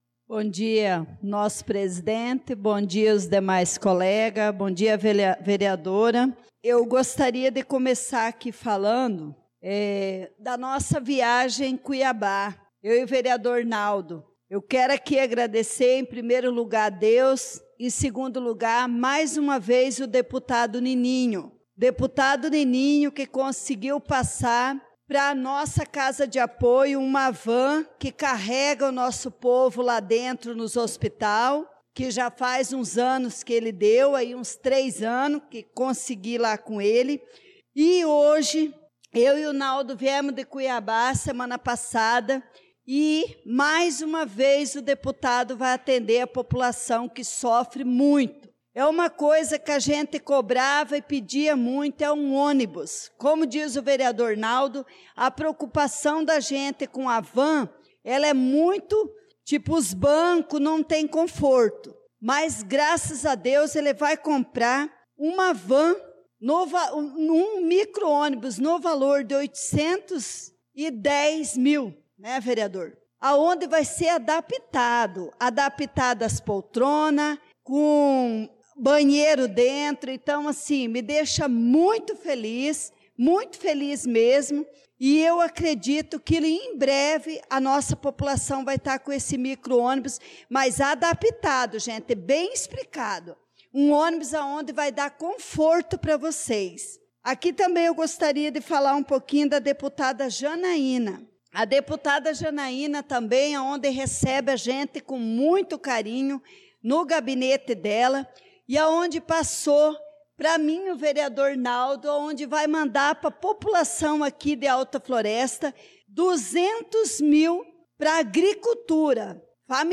Pronunciamento da vereadora Leonice Klaus na Sessão Ordinária do dia 06/03/2025